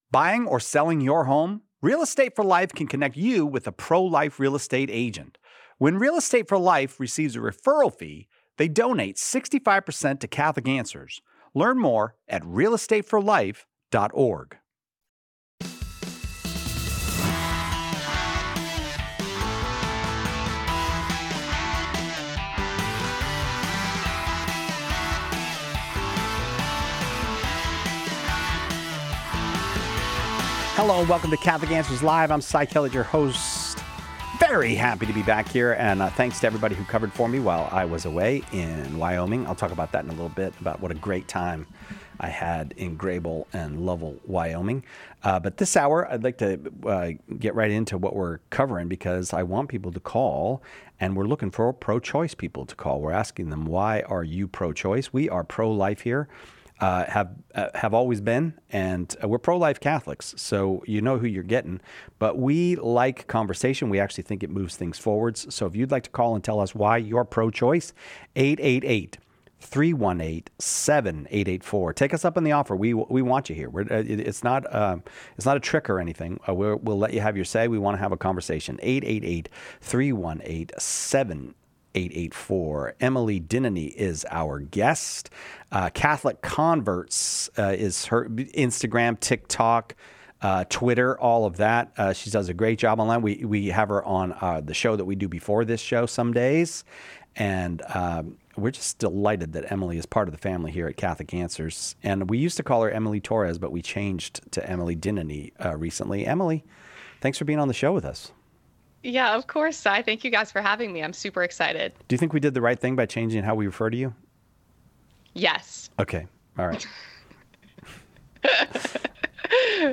The conversation also addresses the role of legislation in protecting the unborn and the implications for fathers in abortion decisions, highlighting the multifaceted nature of these ethical issues.